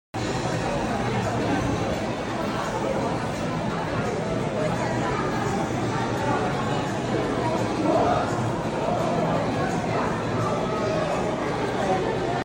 TRANSFORMERS TAKARA TOMY’S BOOTH @ INTERNATIONAL TOKYO TOY SHOW 2025